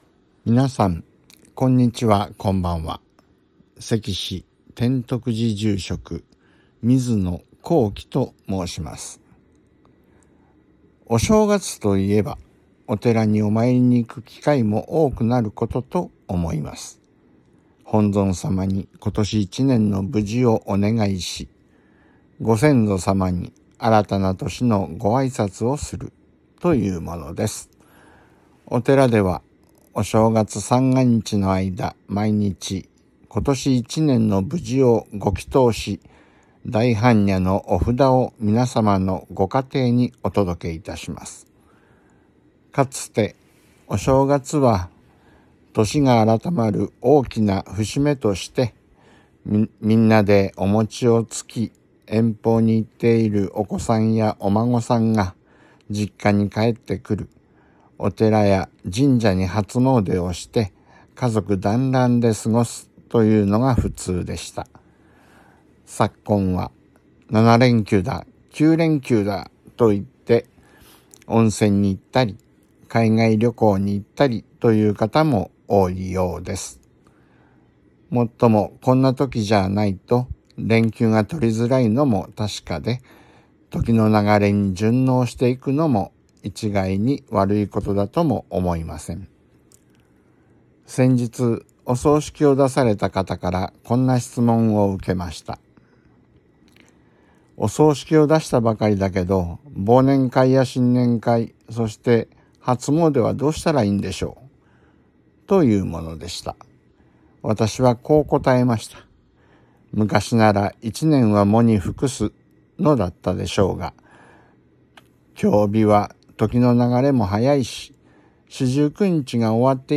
曹洞宗岐阜県宗務所 > テレフォン法話 > 「新年によせて」